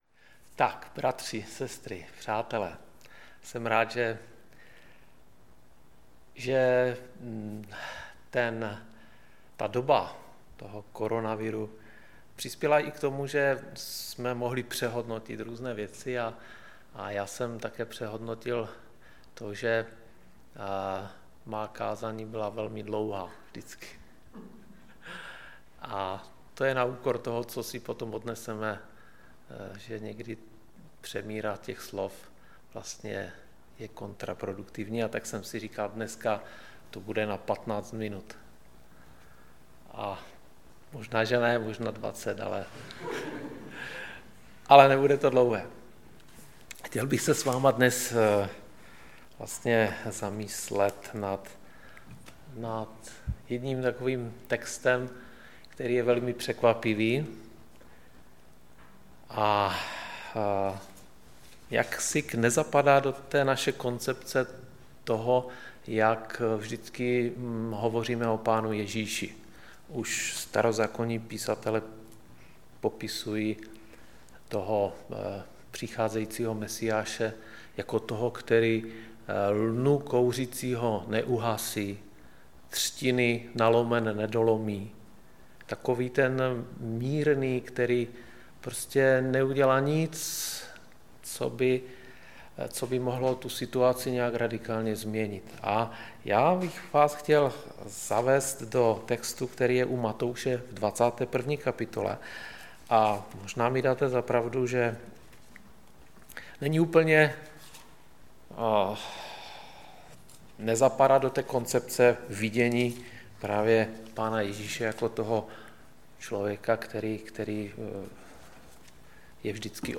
Typ Služby: Kázání